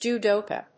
音節jú・do・kà 発音記号・読み方
/‐kὰː(米国英語)/